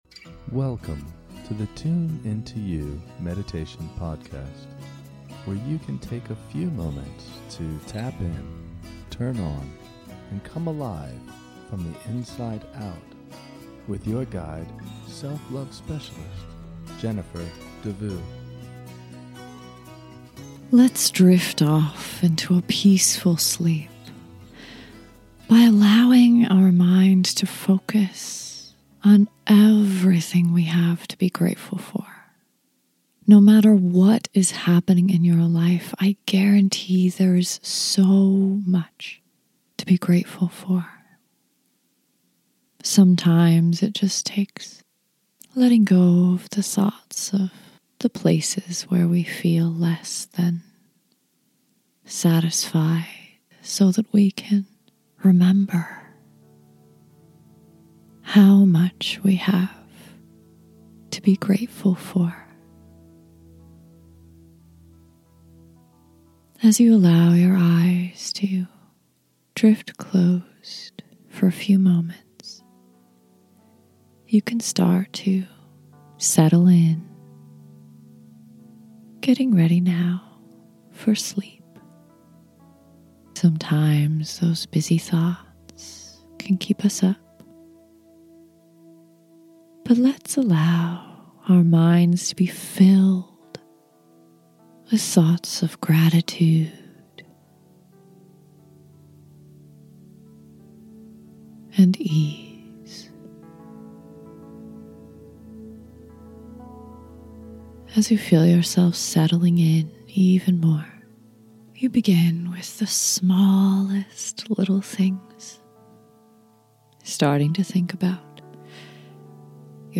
Sleep Meditation for Gratitude
Allow this soothing meditation to lead you into a deep restful sleep.